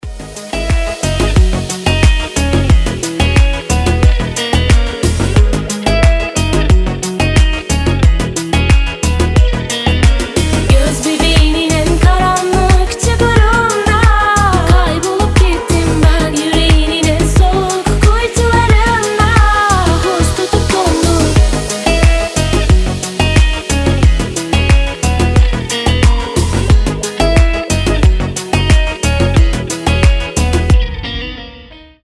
гитара
женский вокал
deep house
красивая мелодия